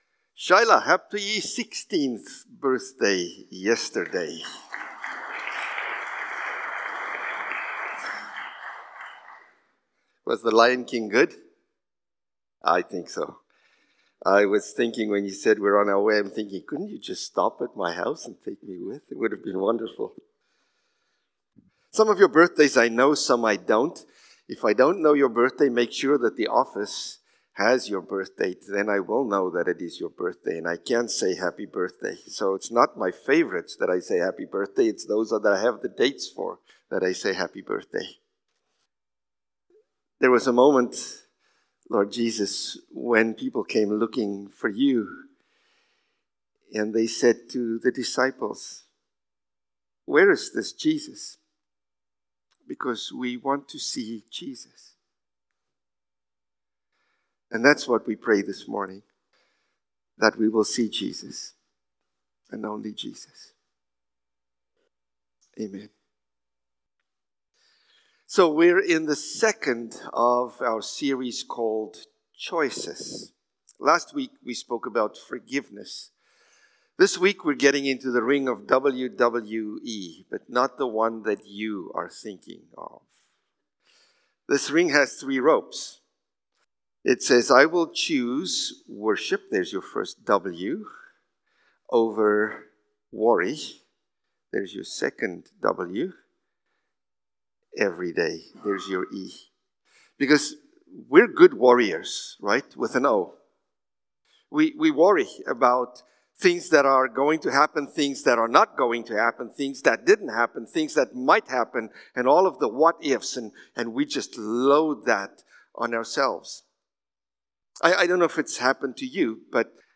May-4-Sermon.mp3